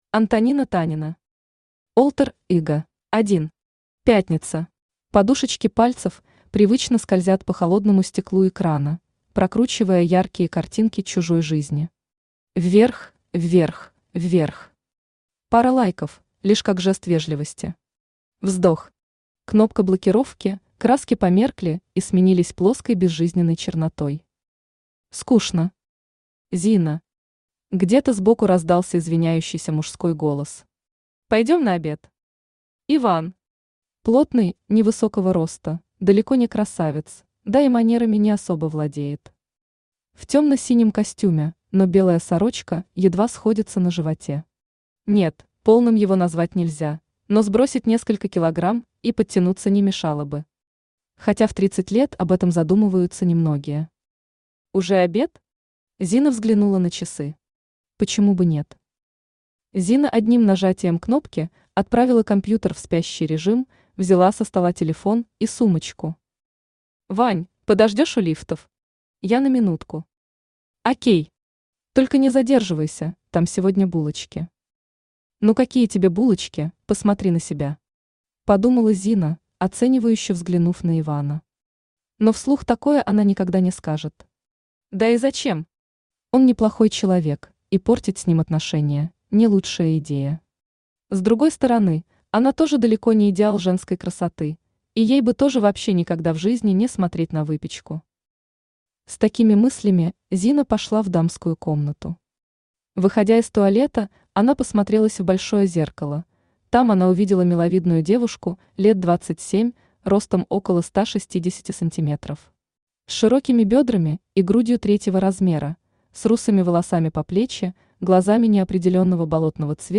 Aудиокнига Alter ego Автор Антонина Танина Читает аудиокнигу Авточтец ЛитРес.